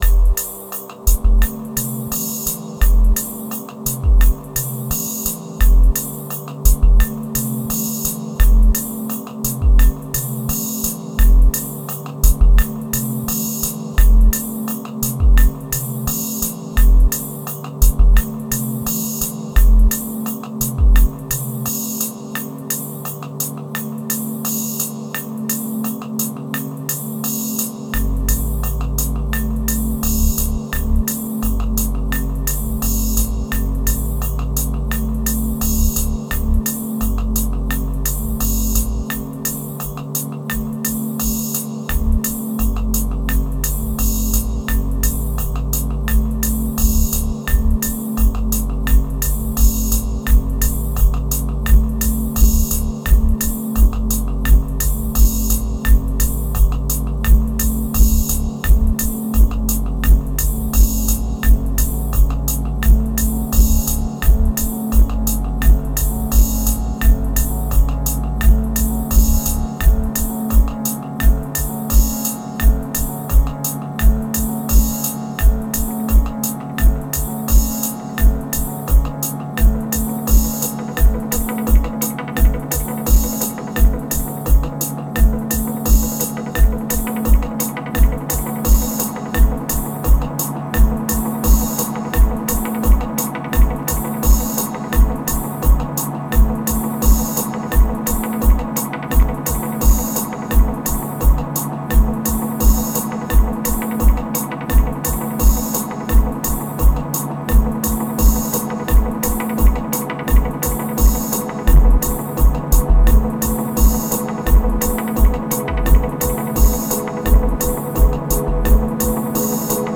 (MPC X + Xone 92 + Eventide Space)